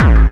VEC3 Bassdrums Dirty 28.wav